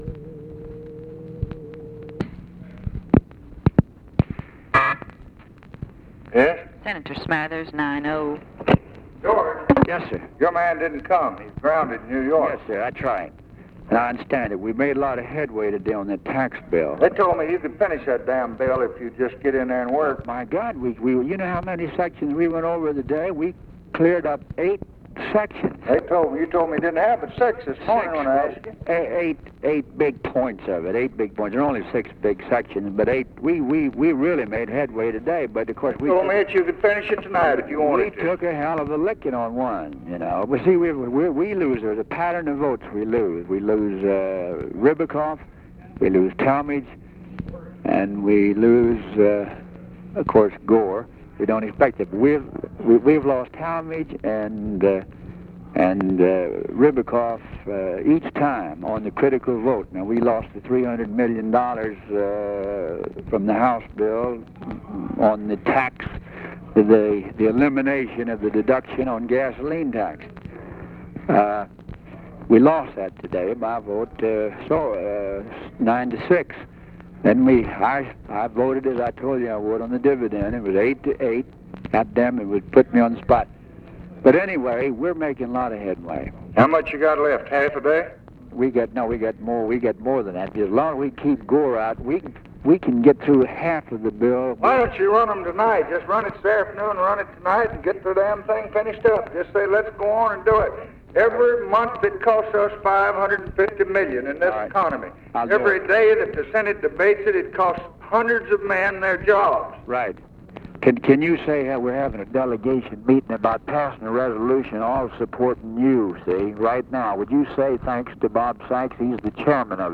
Conversation with GEORGE SMATHERS, January 9, 1964
Secret White House Tapes